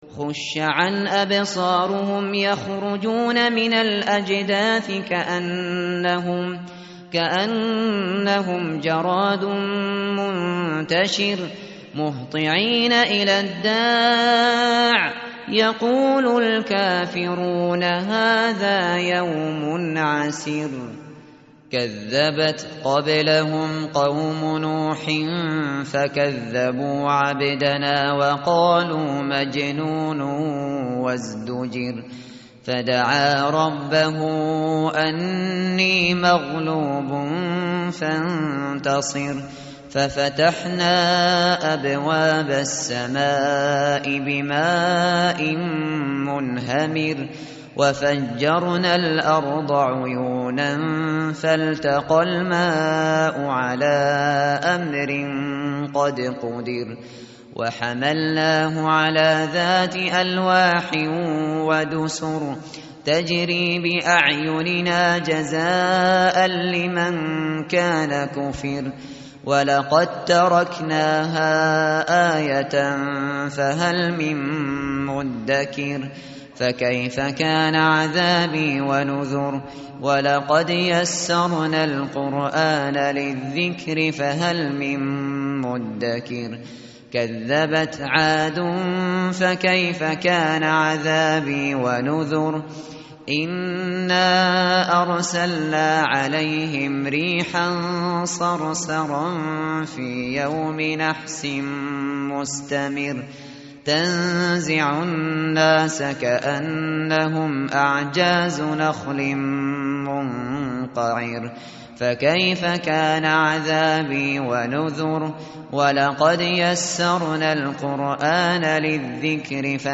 tartil_shateri_page_529.mp3